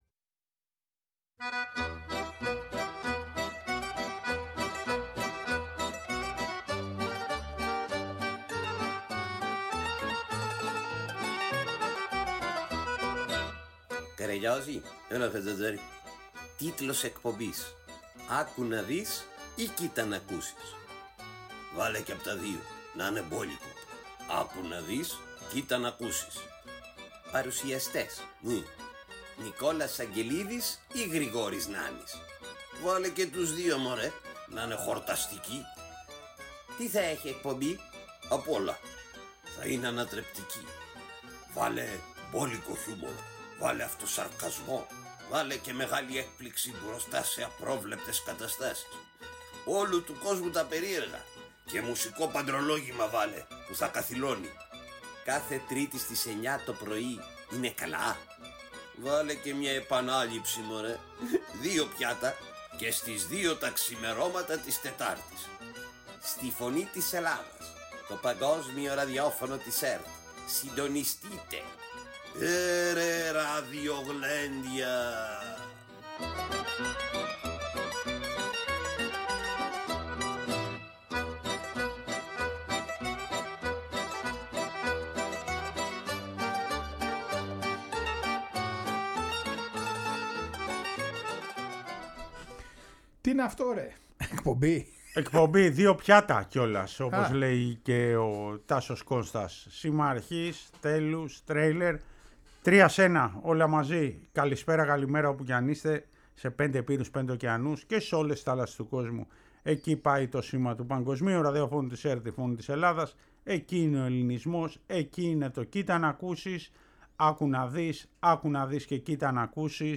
Επίσης μαθαίνουμε την ιστορία και ακούμε τον ύμνο του Ερμή Κιβερίου